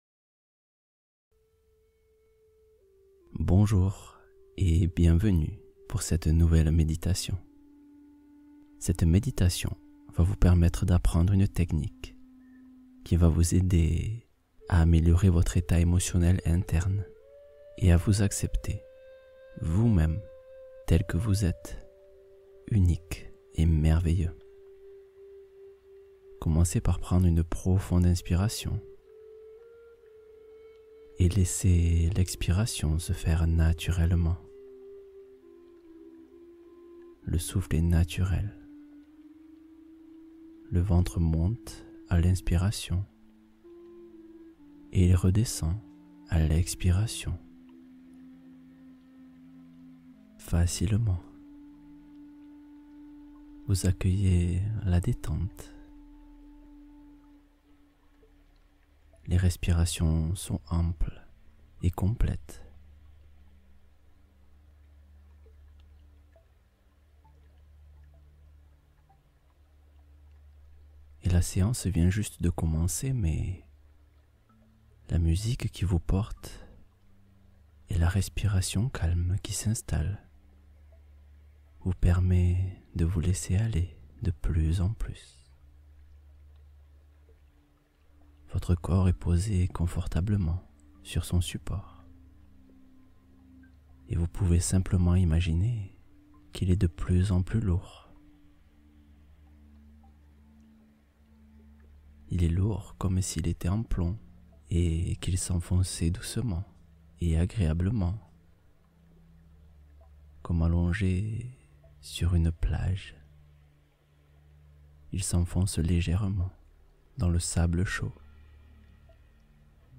Méditation courte revitalisante — Retrouver énergie et clarté d’esprit